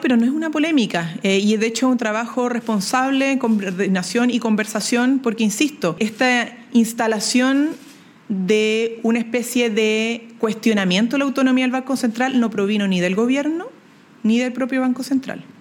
Durante su tradicional punto de prensa de los lunes, la secretaria de Estado manifestó que el informe del Banco Central reconoce que los efectos sobre el empleo son multifactoriales y señaló que en ningún momento se ha intentado que la entidad se inmiscuya en decisiones de política pública laboral.